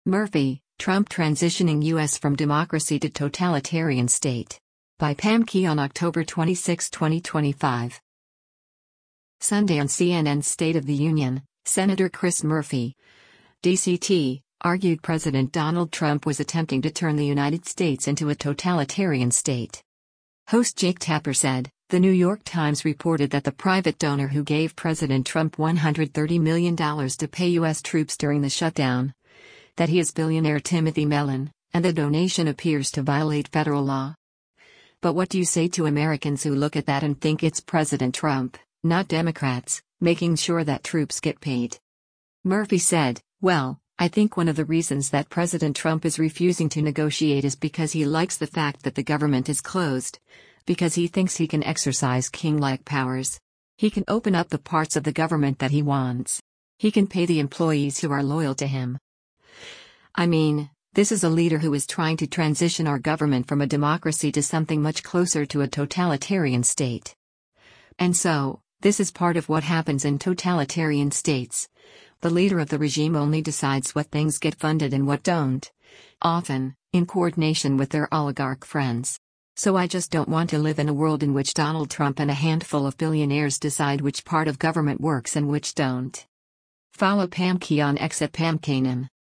Sunday on CNN’s “State of the Union,” Sen. Chris Murphy (D-CT) argued President Donald Trump was attempting to turn the United States into a “totalitarian state.”